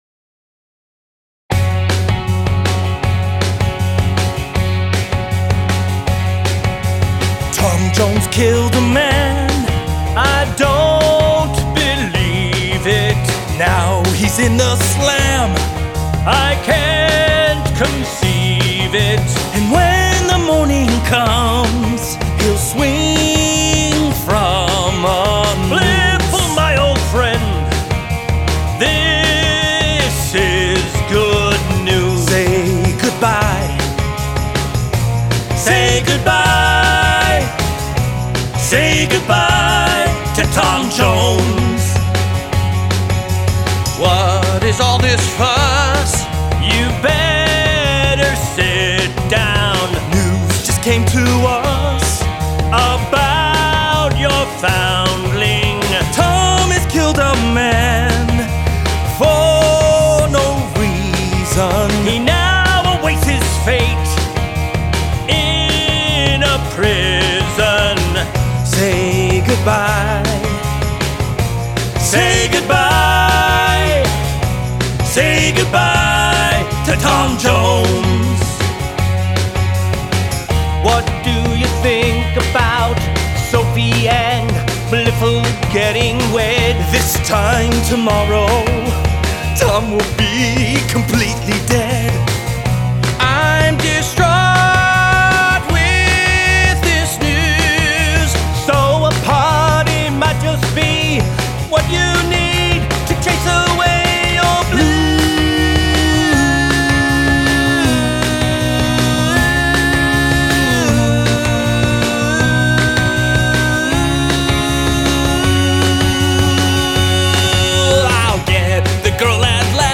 I’ve been chronicling about the recent demo recording sessions I did in NYC for my new musical Tom Jones the Musical.